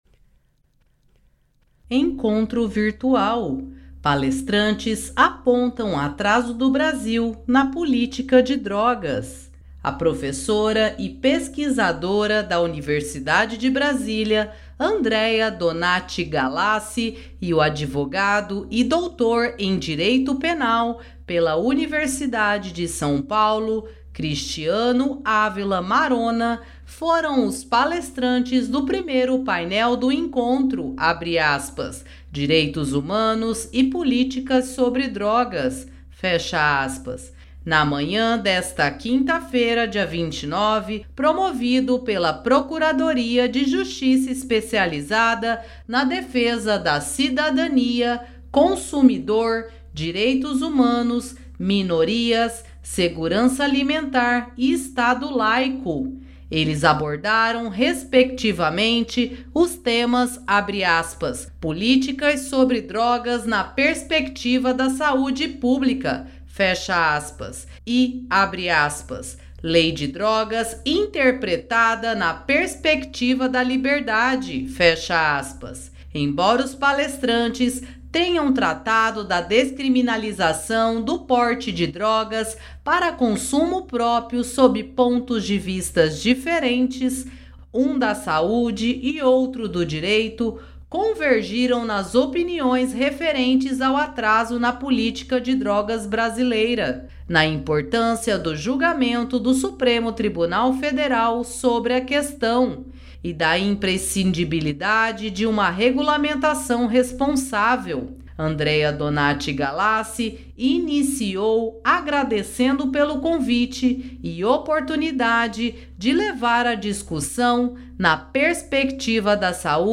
Palestra sobre redução de danos para usuários encerra encontro